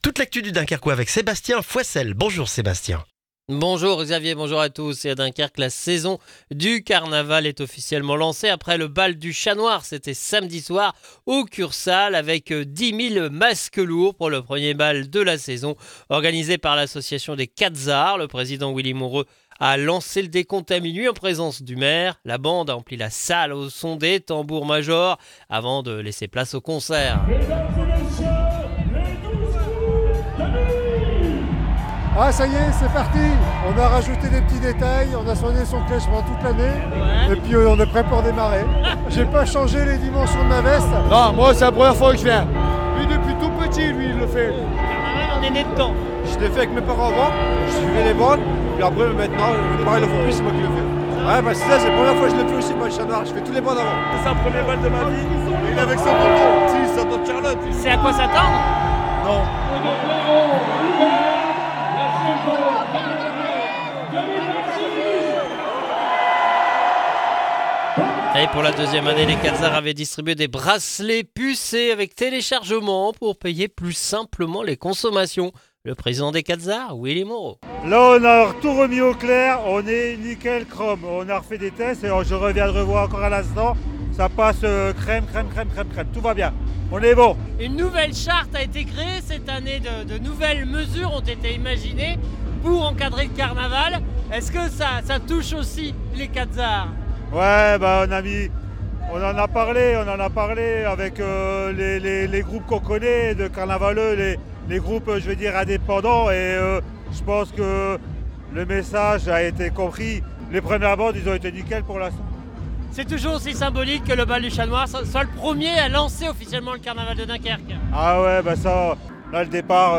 Le journal du lundi 26 janvier dans le Dunkerquois